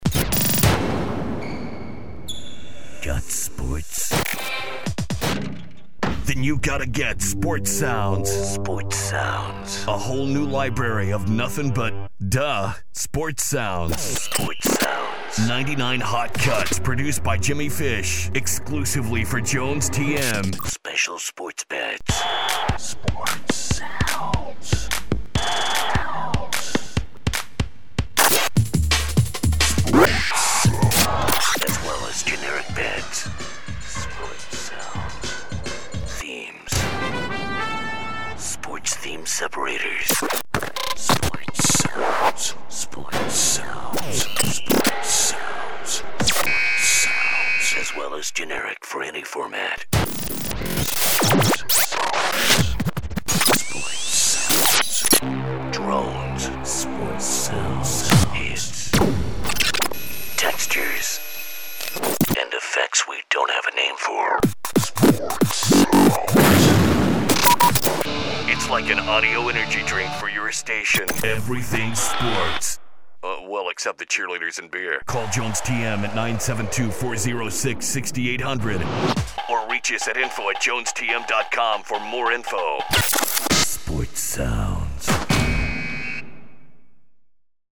production library